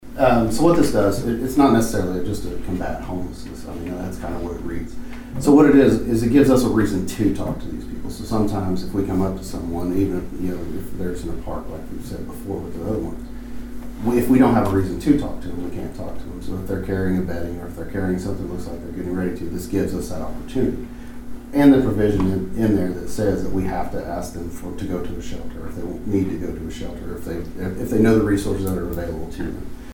The Dewey City Council and Dewey Public Works Authority met for the second time this month on Monday night at Dewey City Hall.
Dewey Police Chief Jimmy Gray talked about the role police plays under this new amendment.